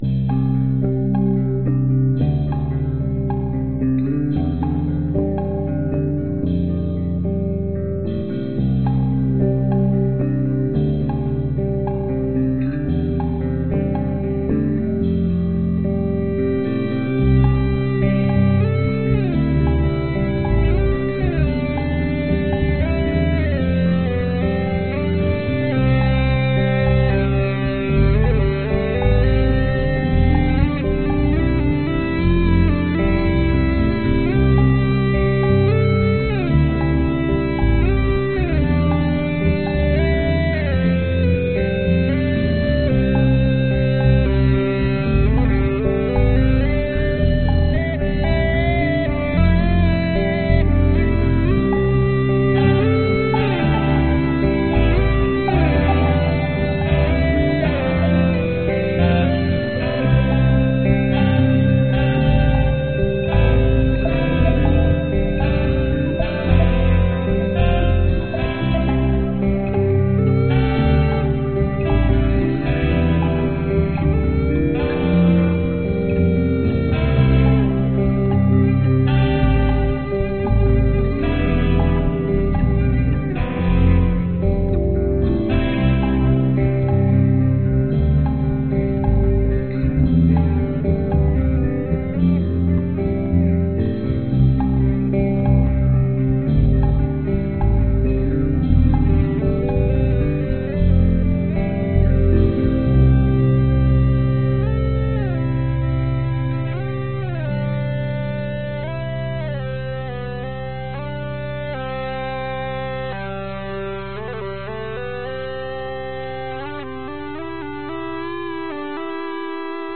我添加了鼓、贝斯、合成器、alt和次中音萨克斯。
downtempo ambient